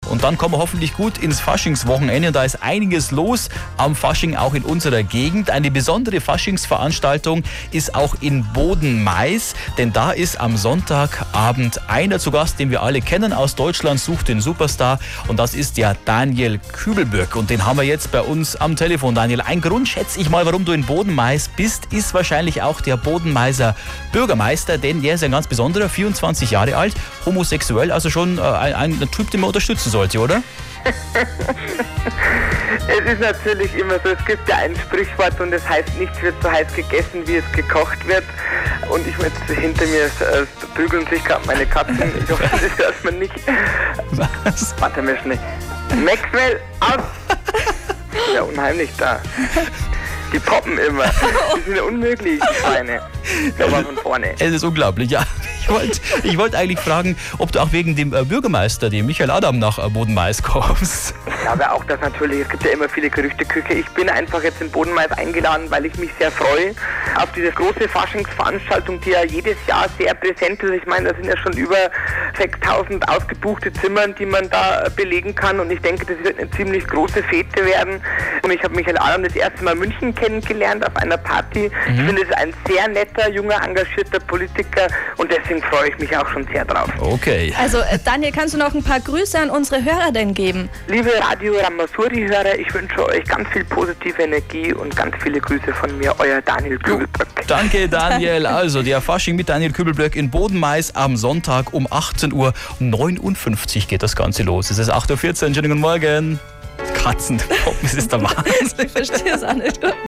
20.02.2009 Interview mit "radio ramasuri"
Daniel Küblböcks Katzen "poppen" bei den Muntermachern!
Eigentlich ging es um die Faschingsparty am Sonntag in Bodenmais mit Daniel Küblböck. Bei Daniels Katzen haben sich während des Interviews aber wohl Frühlingsgefühle entwickelt ...